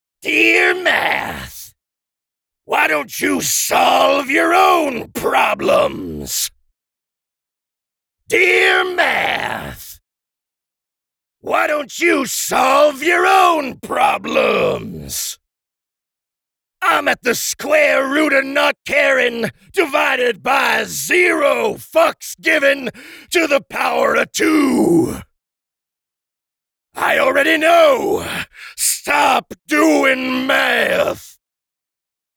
At least it's a banger soundtrack I don't think folks will get too tired of hearing.